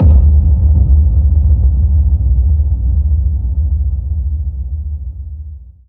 Impact 11.wav